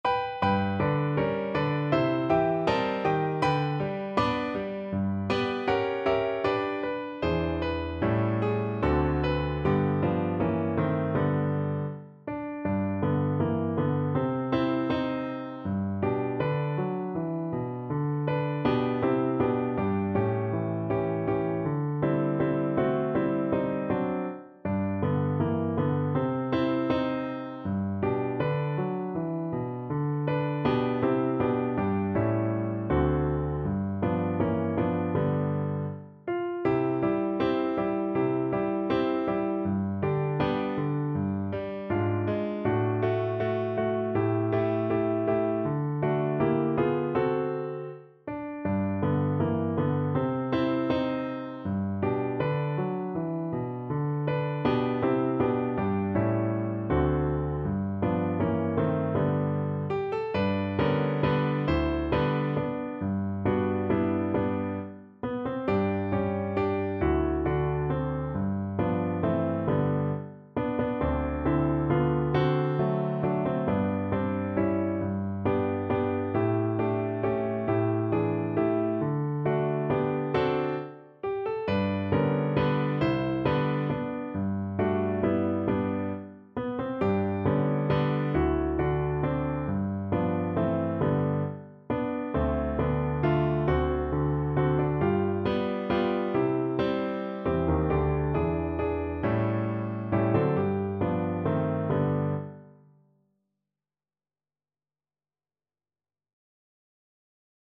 G major (Sounding Pitch) (View more G major Music for Trombone )
4/4 (View more 4/4 Music)
Andante